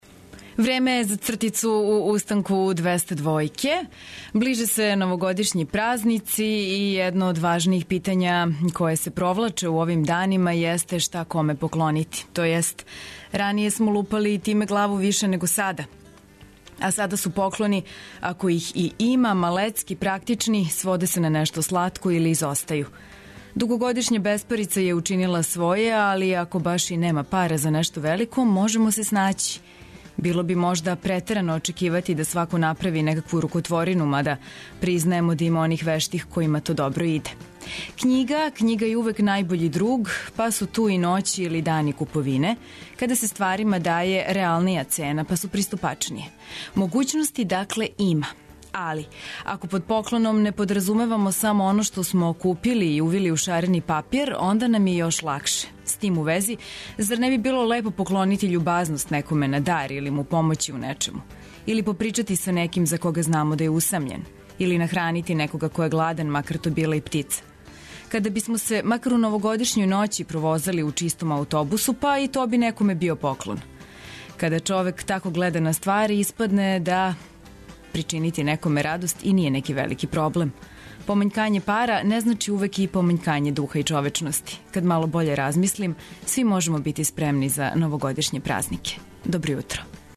Овог јутра смо за вас припремили доста смеха, сервисних информација и веселе музике.
Ту су и рубрике "Глуви барут" и "1001 траг" а чућете и дежурног репортера с београдских улица.